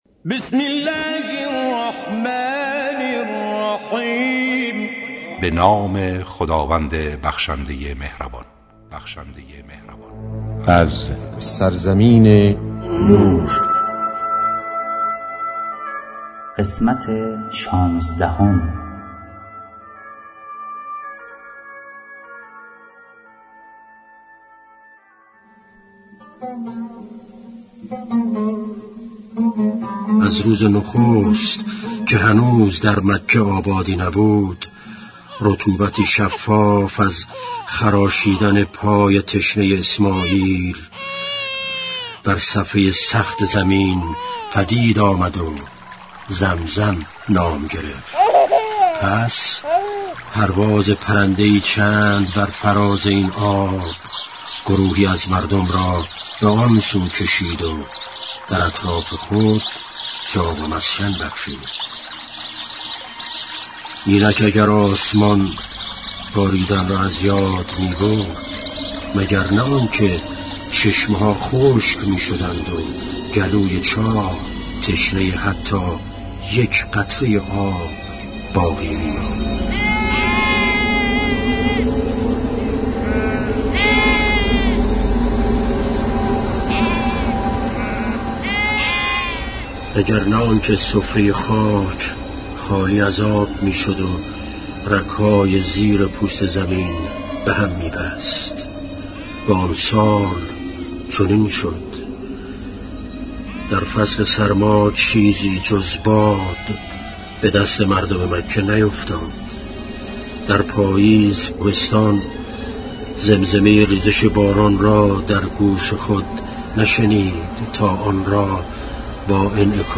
با اجرای مشهورترین صداپیشگان، با اصلاح و صداگذاری جدید
کتاب گویا, کیفیت بالا